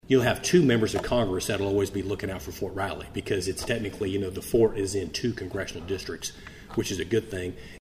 1st District Congressman Tracey Mann was the featured guest at the Manhattan Area Chamber of Commerce Military Relations Committee Luncheon, held Wednesday at the Manhattan Conference Center.